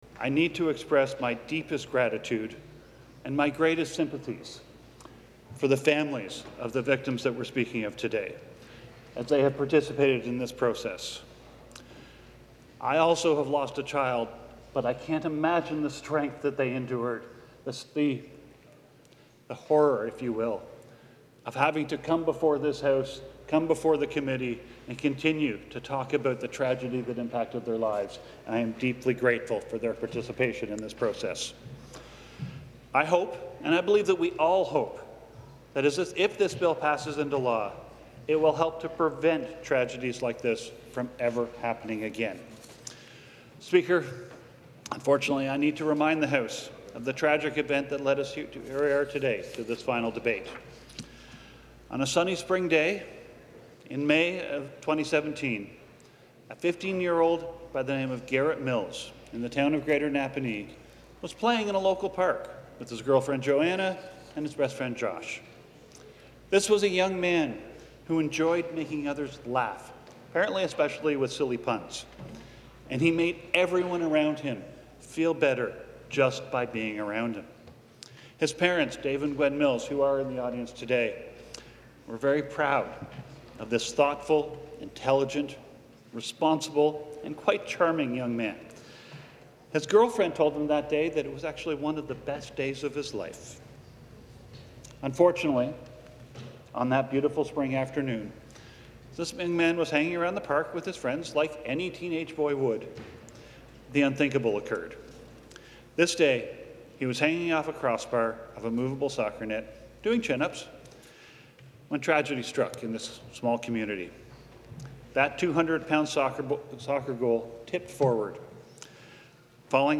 The full presentation of the bill can be heard below.